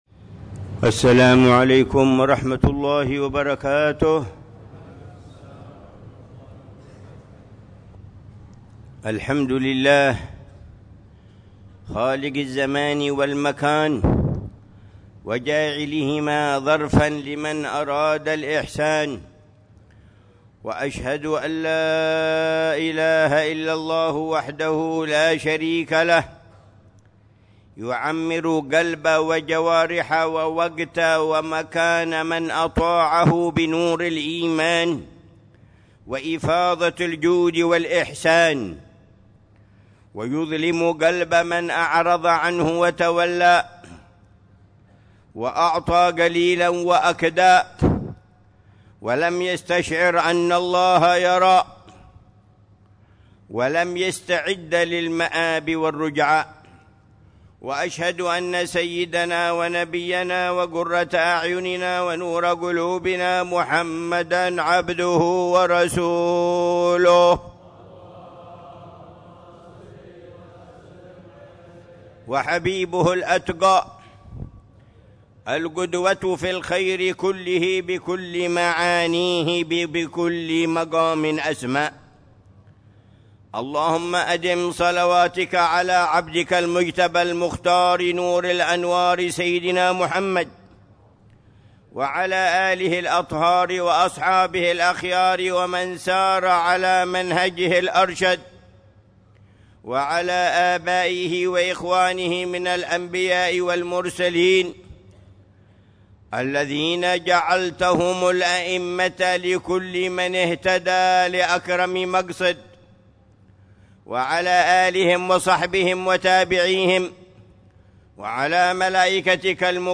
خطبة الجمعة للعلامة الحبيب عمر بن محمد بن حفيظ في جامع الروضة ، بعيديد، تريم، 29 شعبان 1446هـ بعنوان: